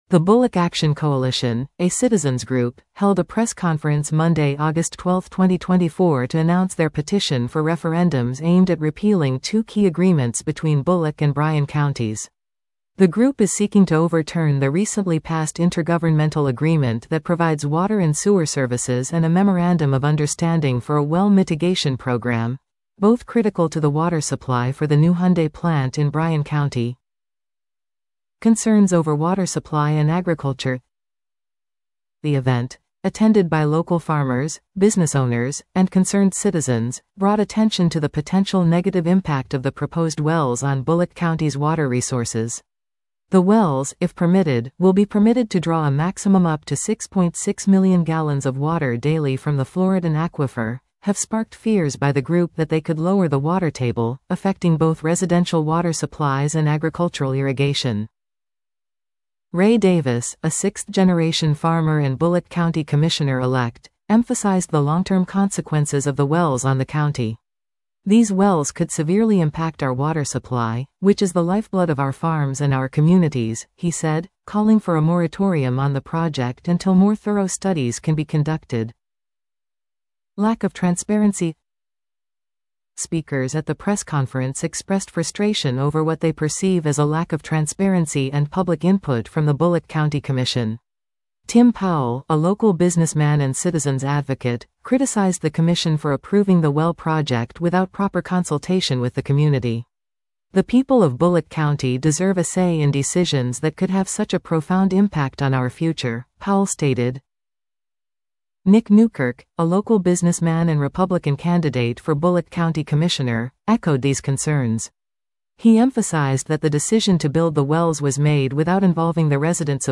Bulloch Action Coalition holds press conference to oppose water agreement
At a press conference organized by the Bulloch Action Coalition, community leaders called for a referendum to let voters decide the fate of the project.